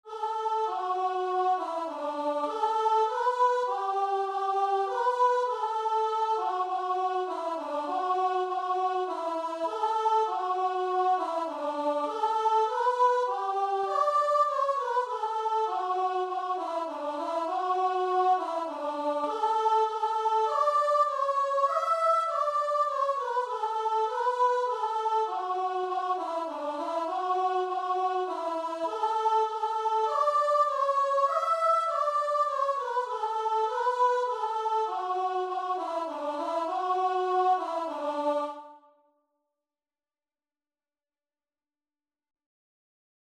Christian
4/4 (View more 4/4 Music)
Classical (View more Classical Guitar and Vocal Music)